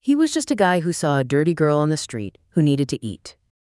Gemini-2.0-Flash-Kore-Voice